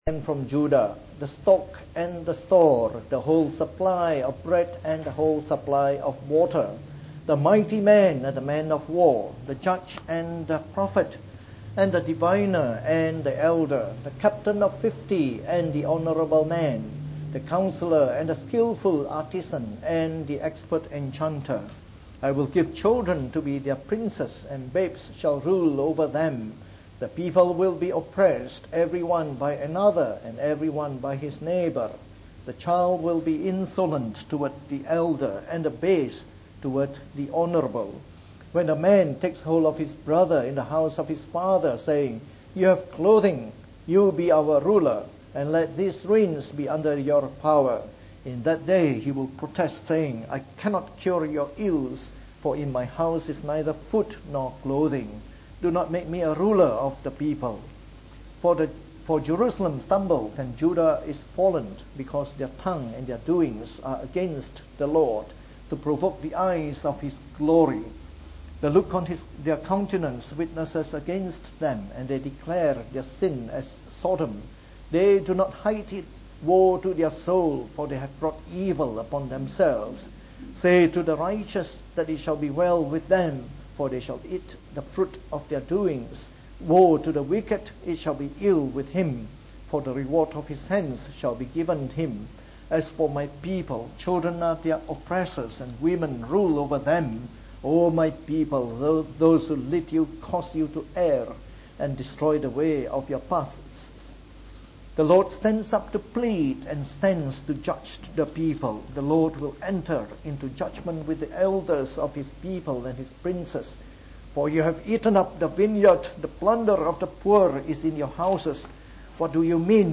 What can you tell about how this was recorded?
From our new series on the book of Isaiah delivered in the Morning Service.